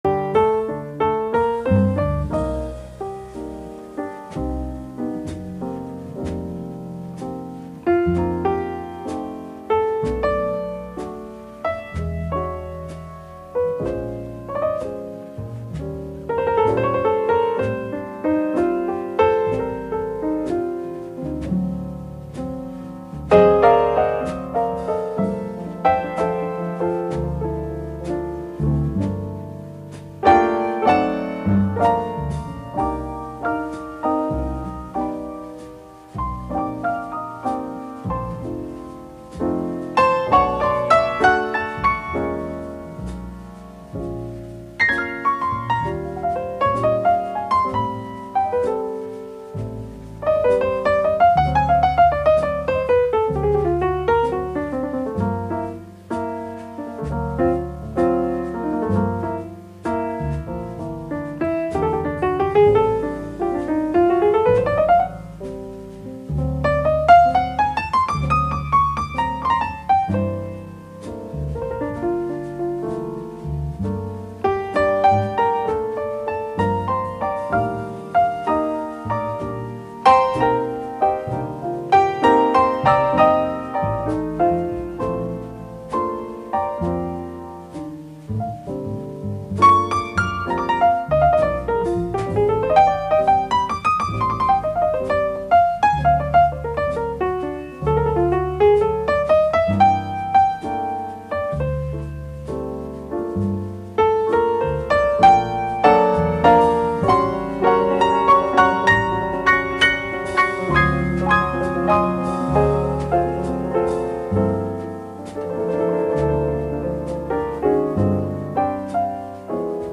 jazz standard